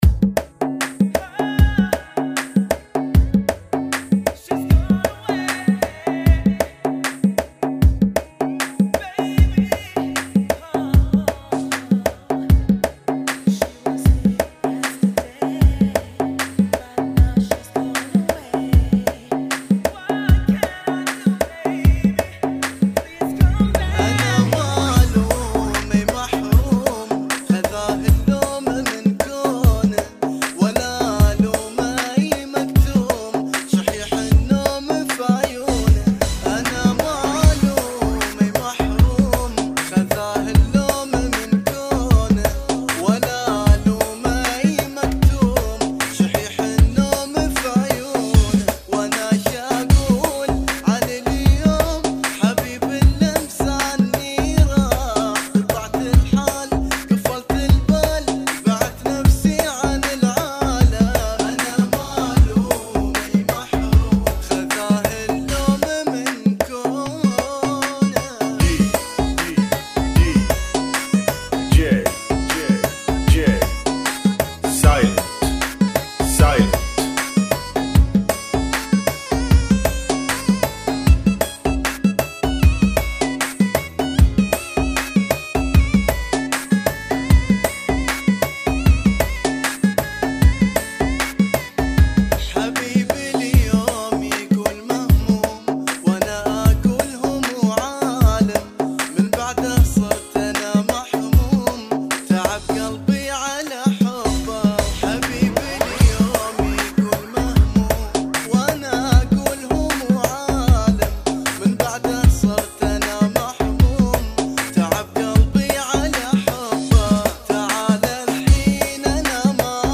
77 BPM